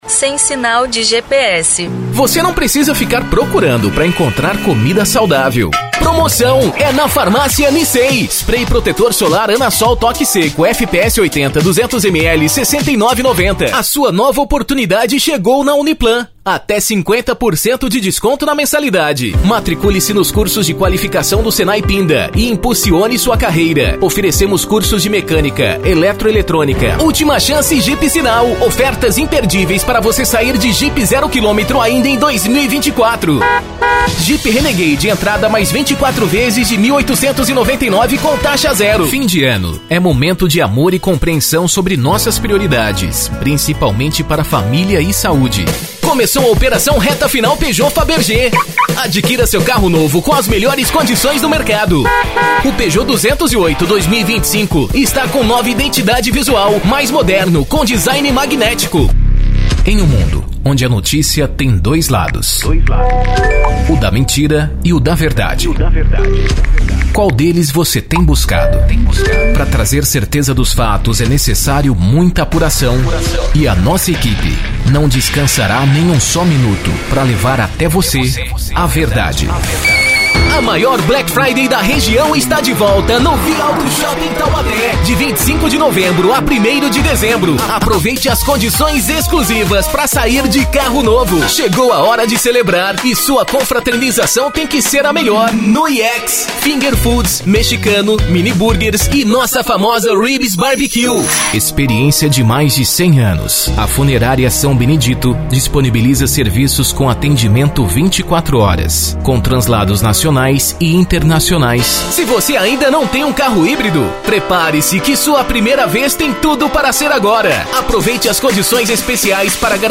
Spot Comercial
Vinhetas
Animada
Caricata